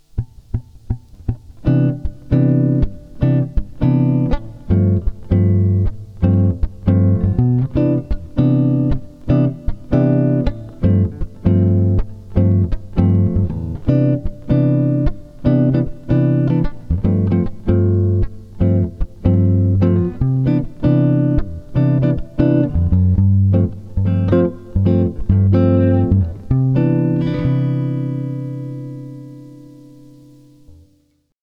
Basic backing chord tracks are below.
C Major Backing fast tempo (985 KB)